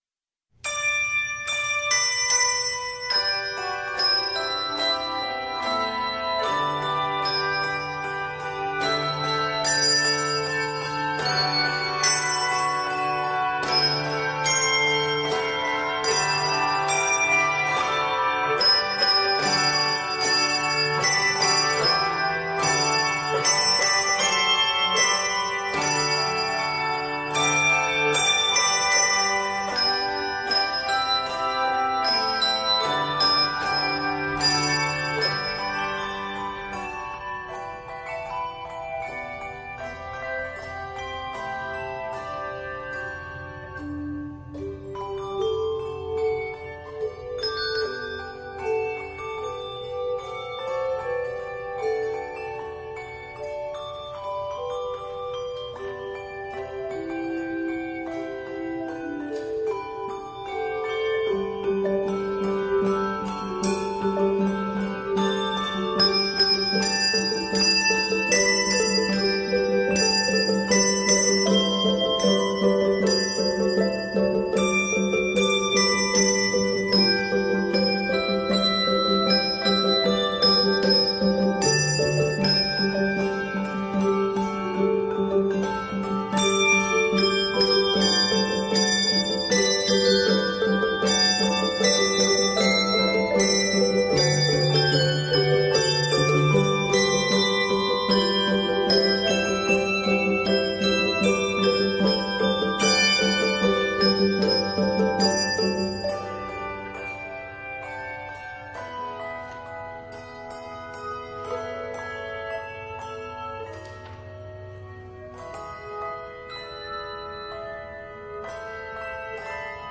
Feisty and fanfare-ish